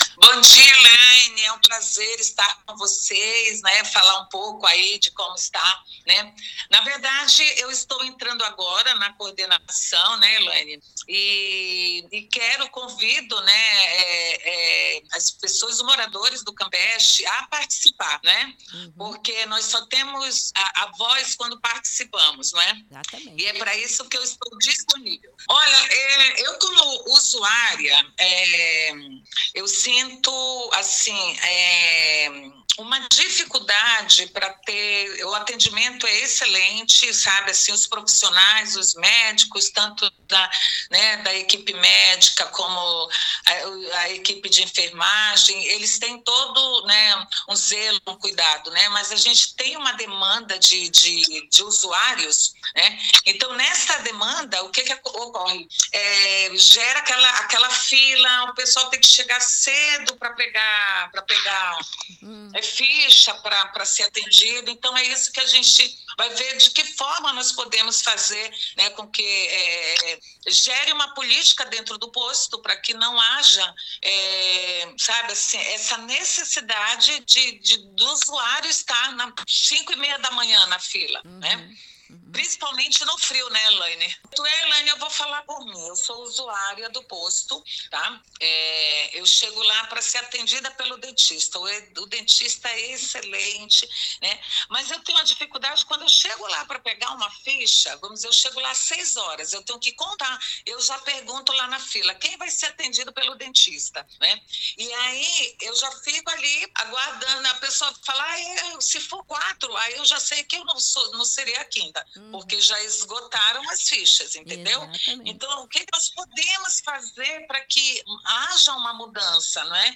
E ela reforça o convite para  reunião.